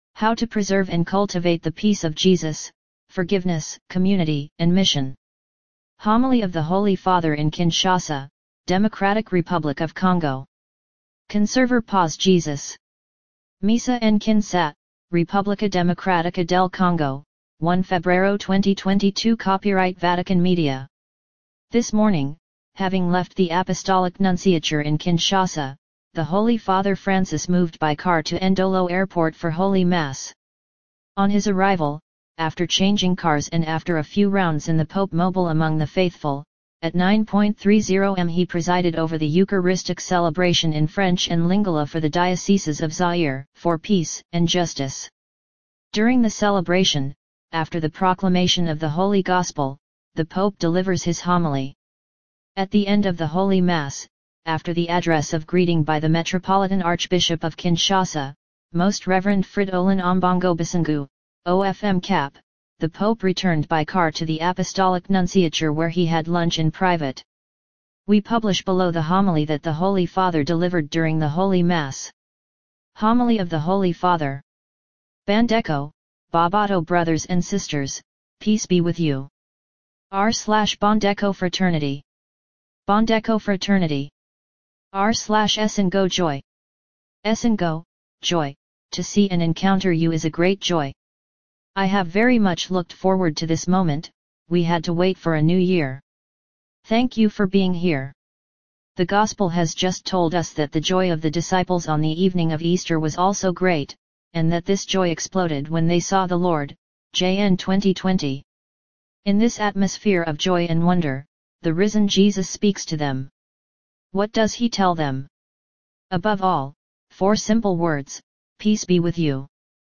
Homily of the Holy Father in Kinshasa, Democratic Republic of Congo
During the celebration, after the proclamation of the Holy Gospel, the Pope delivers his homily.
Homily of the Holy Father